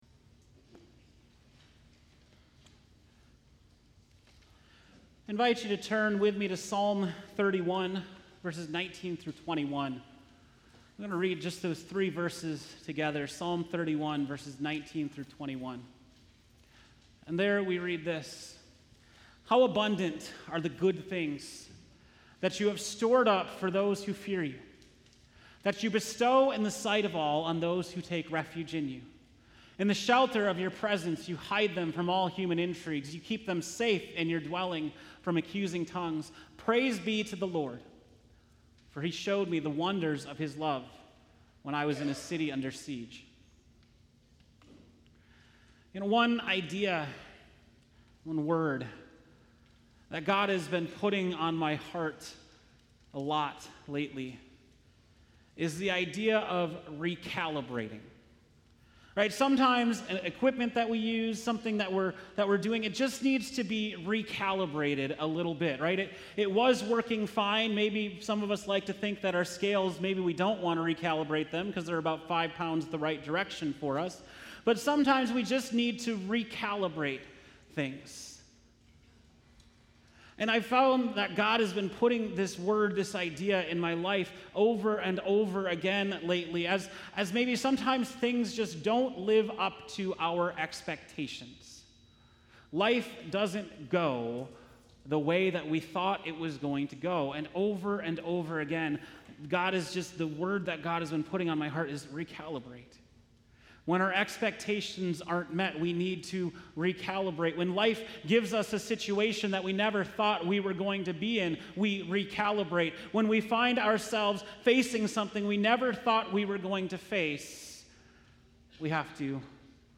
August 4, 2019 (Morning Worship)